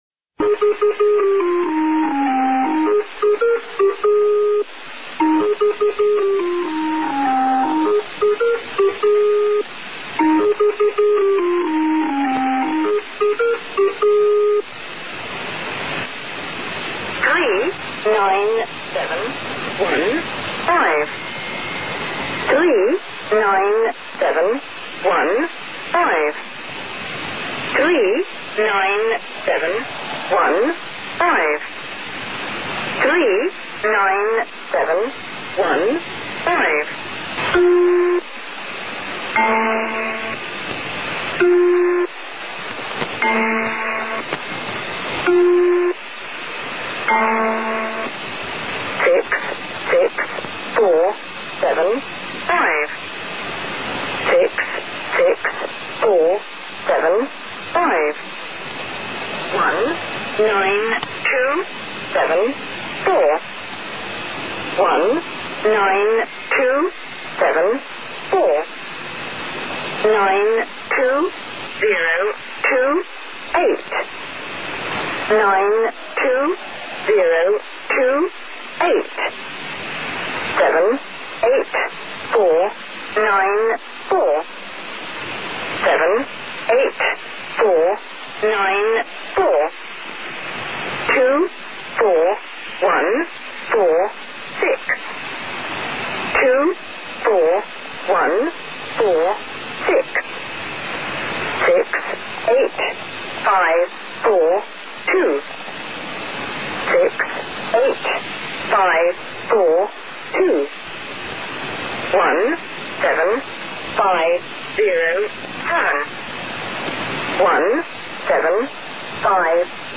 Enkele opnames van nummerstations
Lincolnshire Poacher Birtse buitenlandse geheime dienst MI6, a.k.a. SIS, met zendstation in Cyprus, en zeer herkenbare introductiesignaal, de Engelse folk melodie The Lincolnshire Poacher.